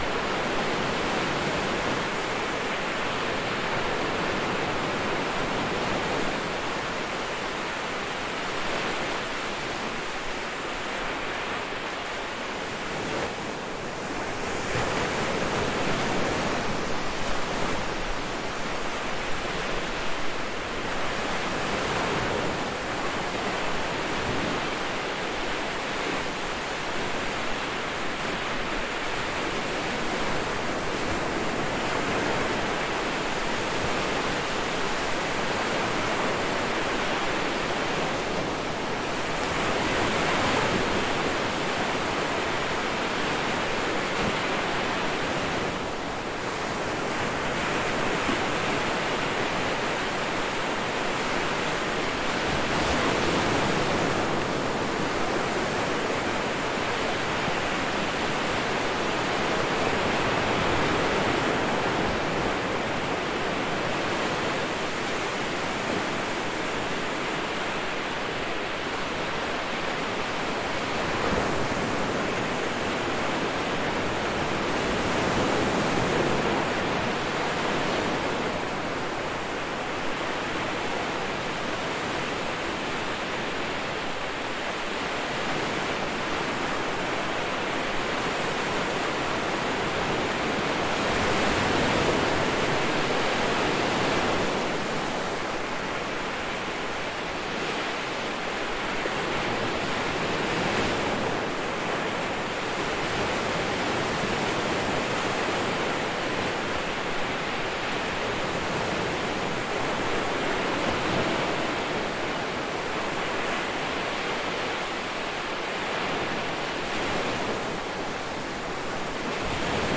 描述：在波罗的海的海滩上用TASCAM DR22WL设备录制
Tag: 海岸 波罗的海 雾GY 早晨 肿胀 抛光